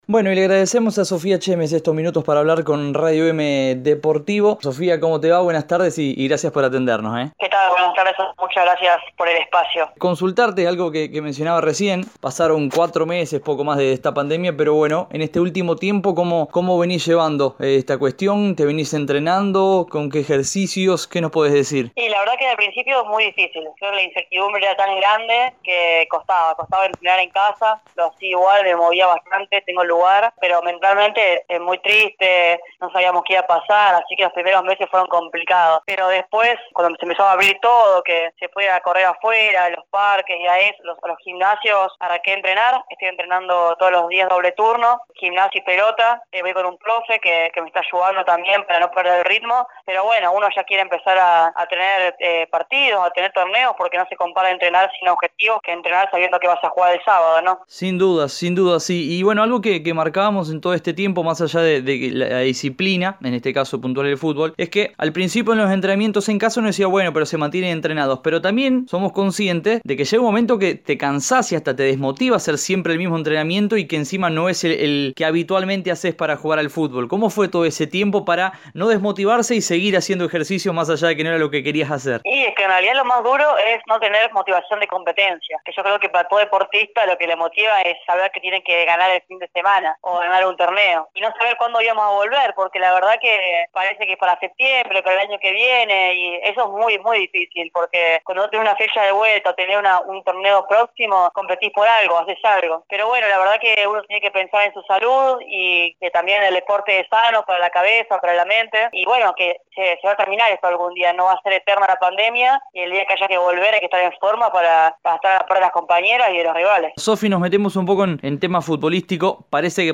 con paso en Unión y de ultima temporada en Racing habló en Radio Eme Deportivo sobre este parate deportivo producto de la pandemia y las de seguir creciendo futbolísticamente.